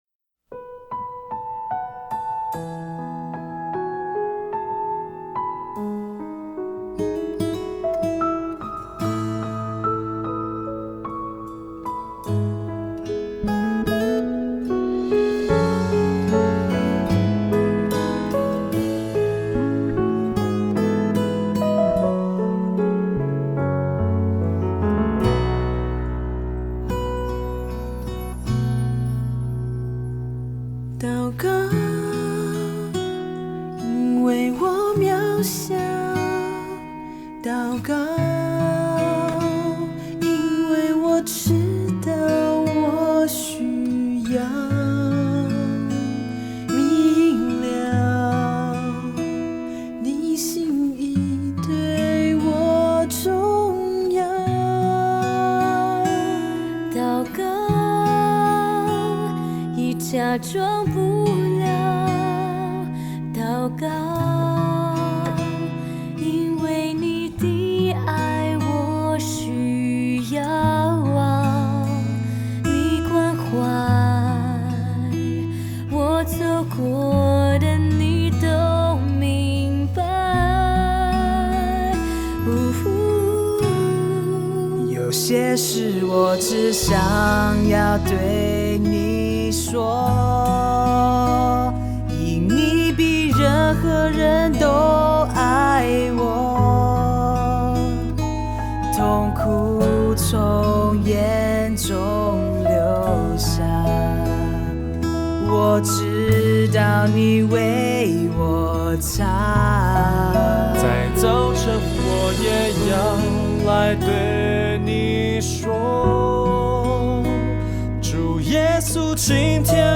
以原声（Acoustic）的方式呈现